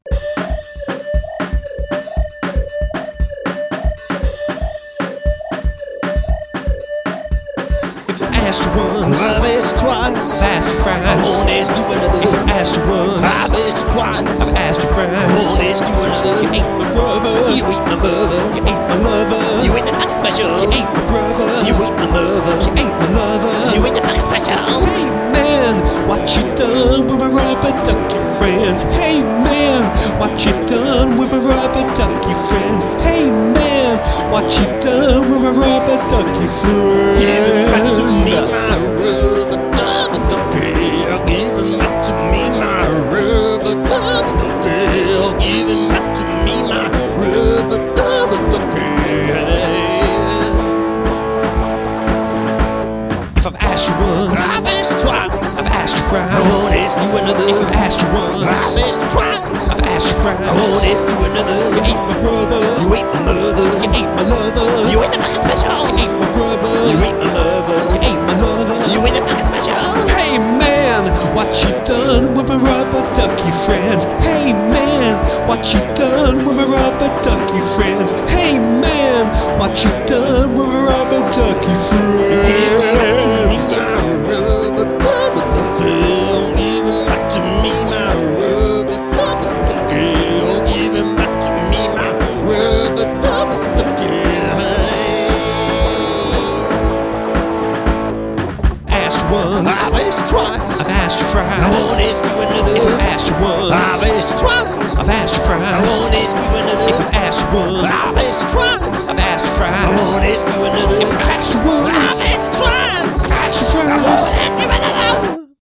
It was a romp to write and it's a romp to listen to.